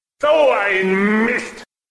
doh i sound effects